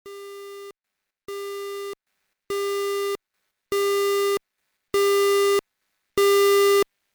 Alert Tone - AS2220.1